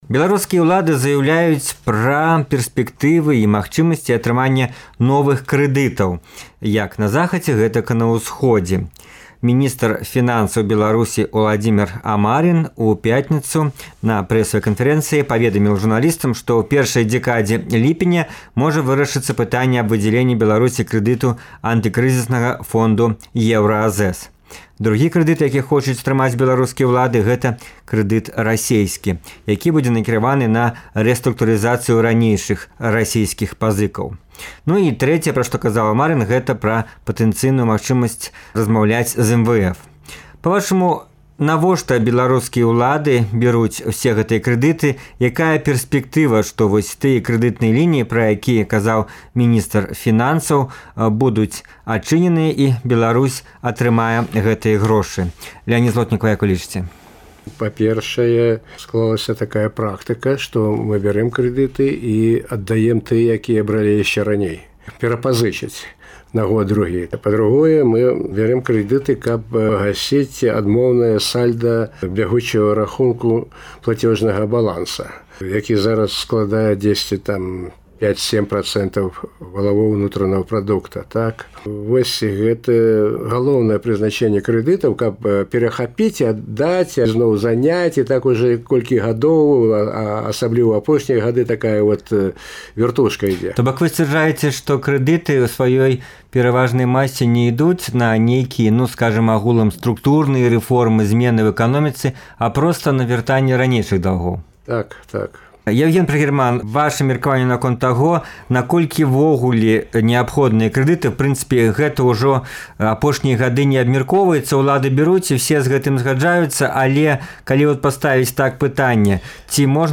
На гэтыя ды іншыя пытаньні ў перадачы «Экспэртыза Свабоды» адказваюць эканамісты